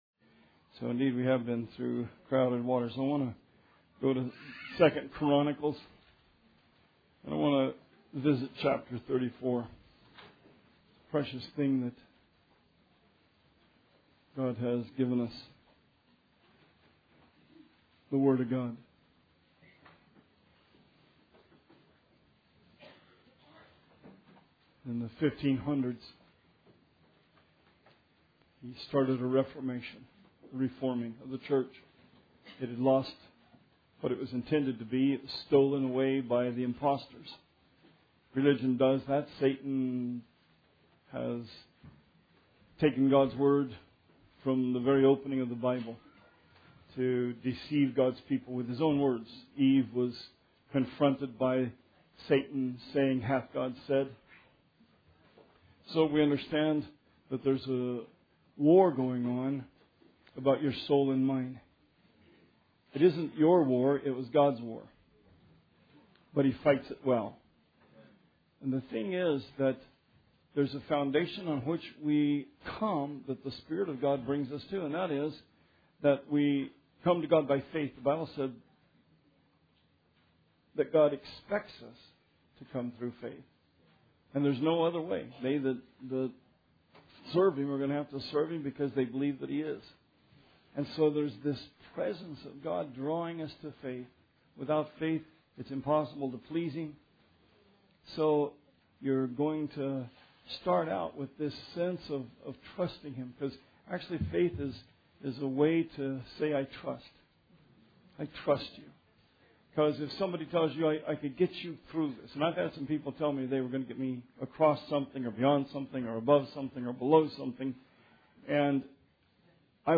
Sermon 7/3/16